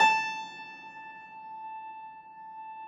53g-pno17-A3.wav